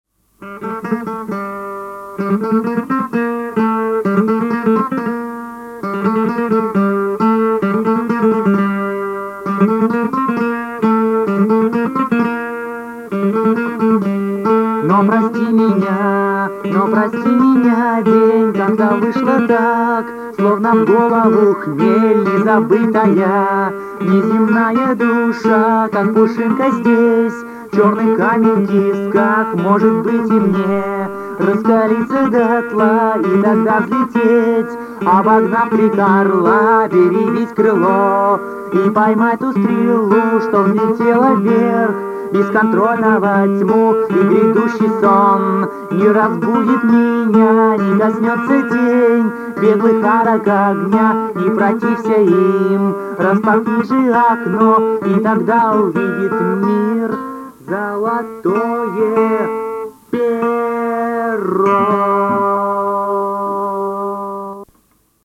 Качество: mp3, оцифровка с кассеты.